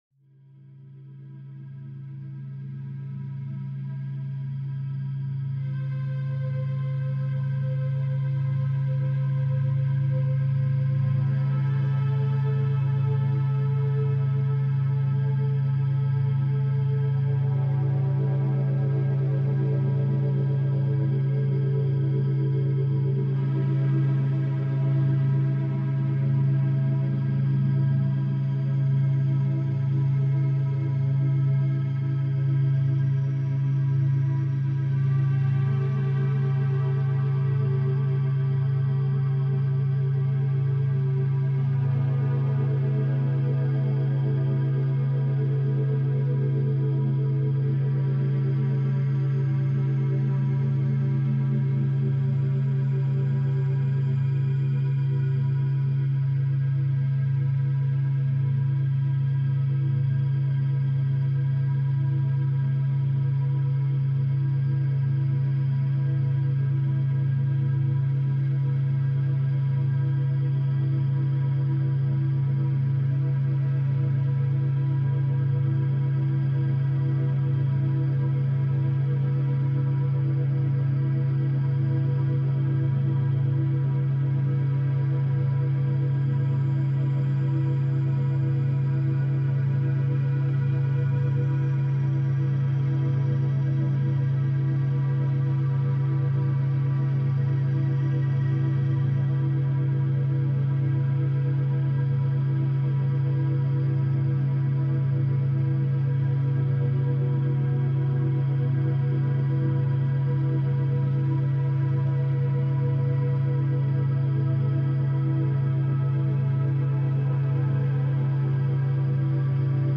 Café d’étude paisible · méthode essentielle 2 heures ressentie